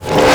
VEC3 FX Reverse 47.wav